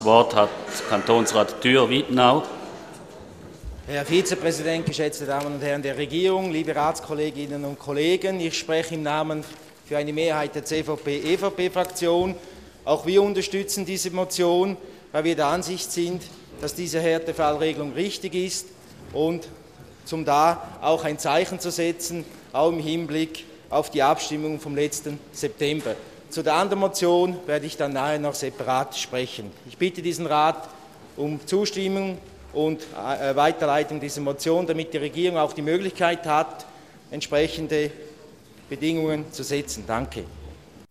Session des Kantonsrates vom 25. bis 27. Februar 2013